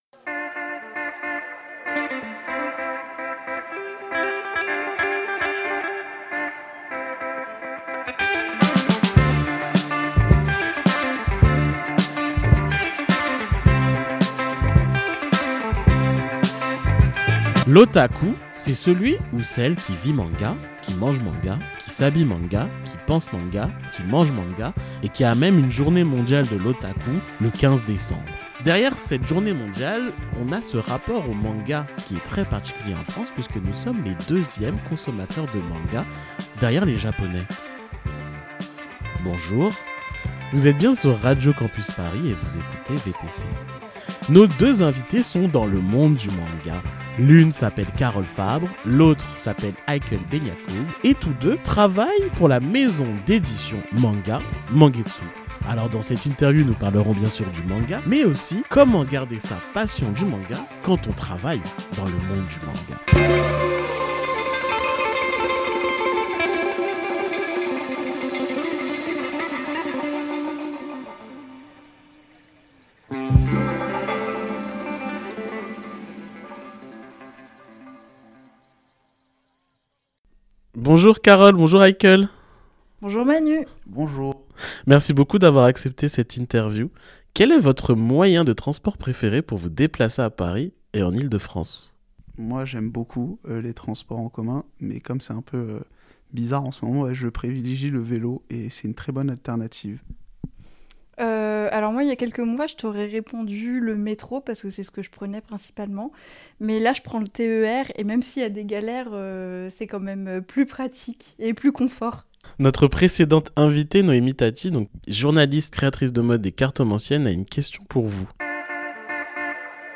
Partager Type Entretien Société Culture jeudi 7 décembre 2023 Lire Pause Télécharger Lire du manga, comme des gens aigris peuvent le dire, ça reste de la lecture !